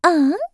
cheers1.wav